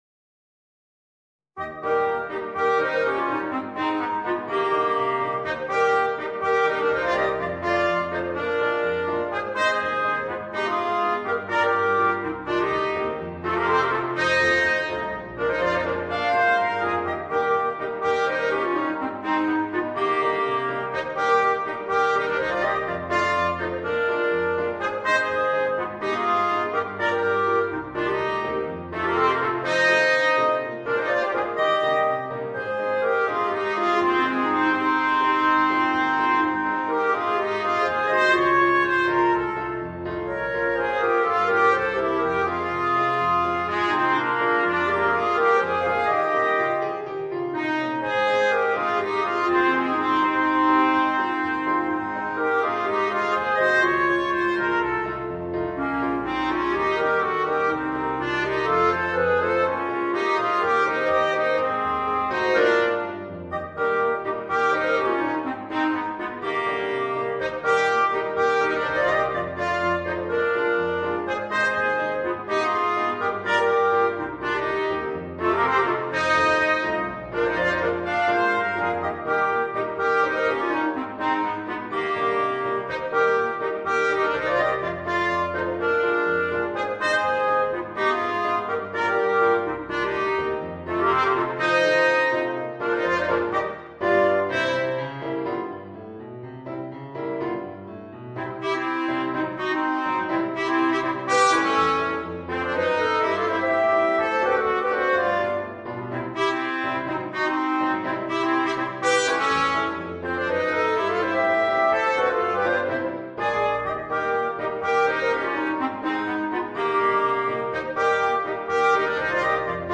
Voicing: Clarinet, Trumpet and Piano